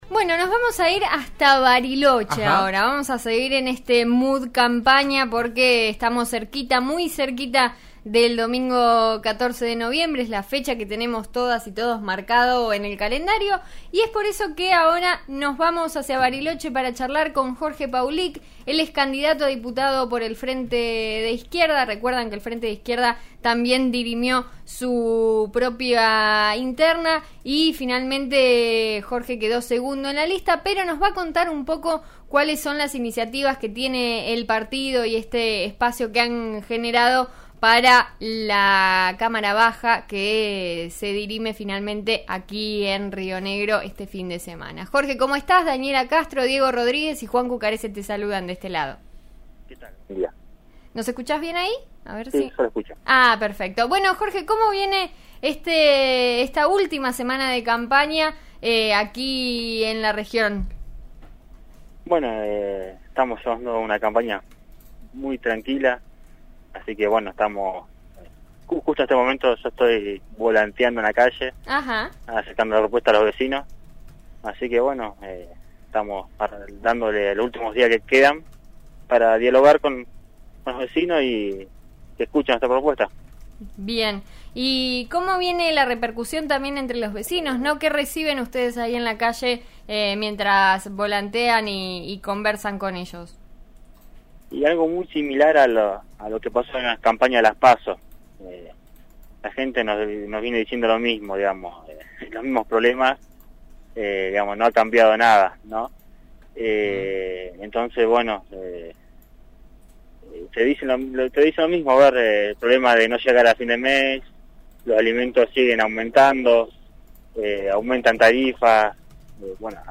charló hoy con «En eso estamos» de RN RADIO